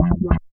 81 BS LICK-R.wav